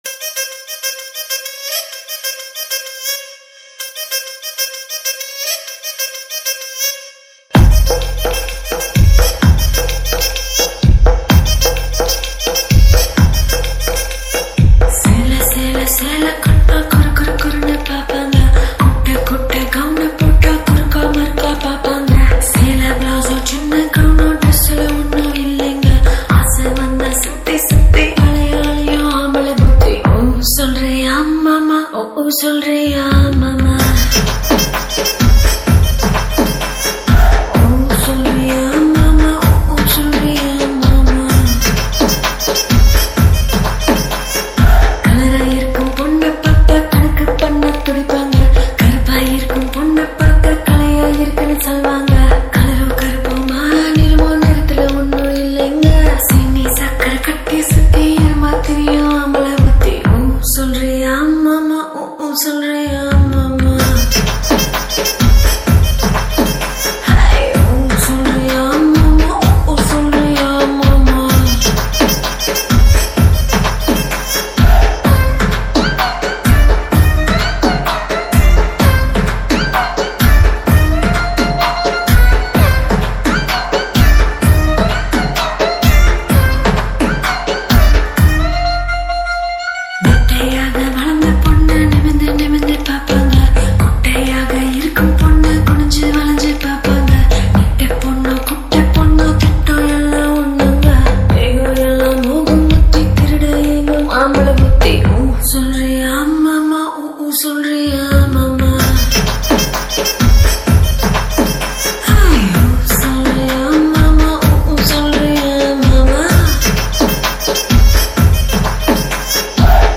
Tamil 8D Songs